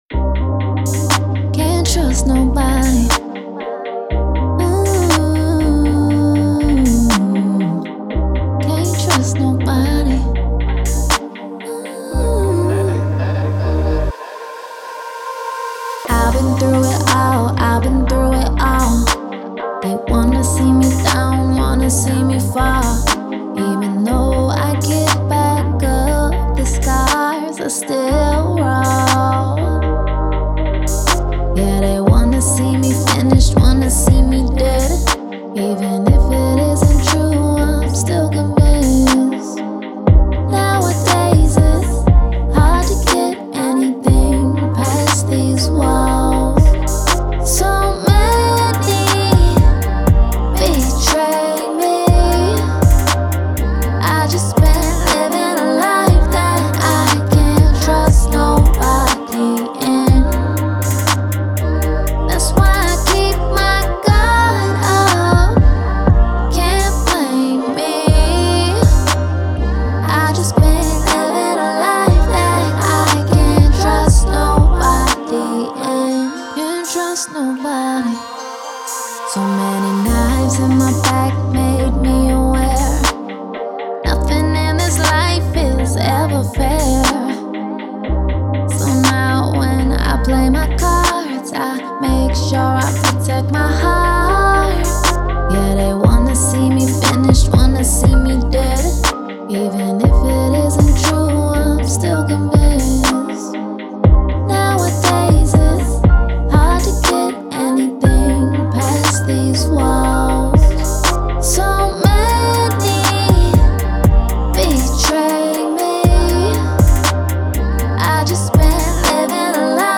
R&B
C Minor